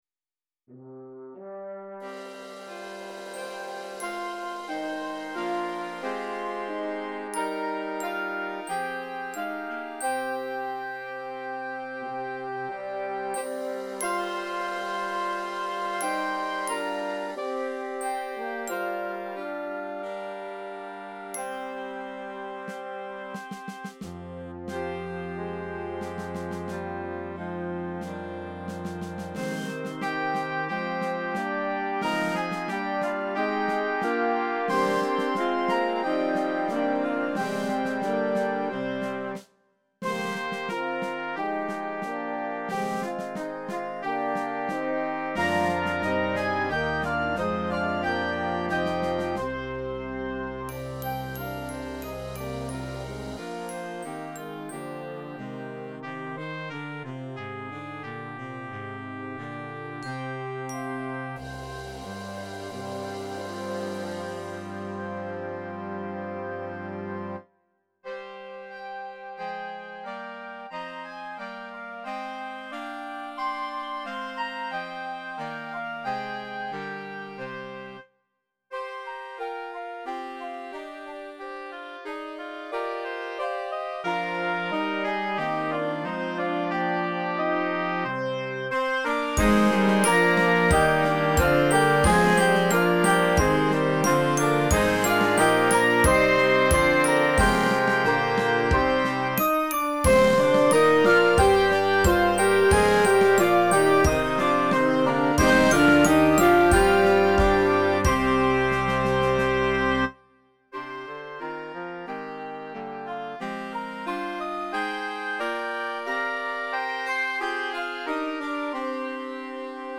The following are are some of my compositions realized in midi.
A high school concert band piece, The Triumph of Grainne Mhaol: